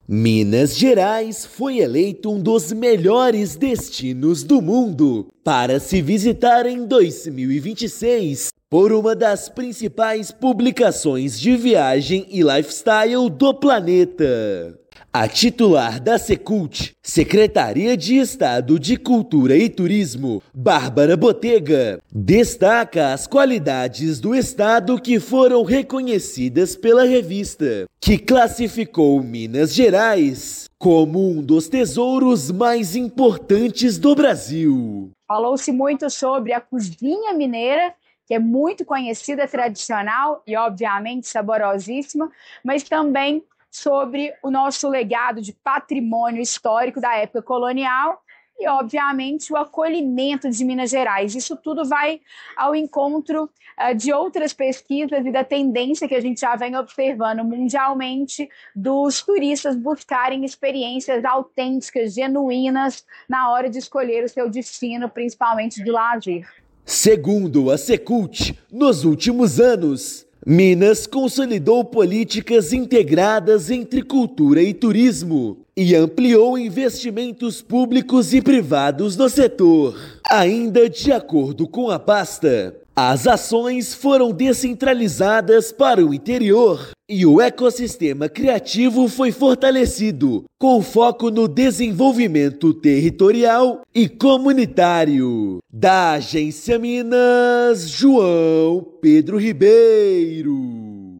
[RÁDIO] Minas Gerais é eleito um dos melhores destinos do mundo para 2026 por revista internacional
Revista internacional destaca a força da cultura, da gastronomia e da hospitalidade mineira como diferenciais globais. Ouça matéria de rádio.